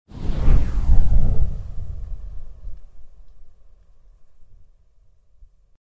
Звуки перехода, смены кадра
Мягкий звук перехода, не громкий, а тихий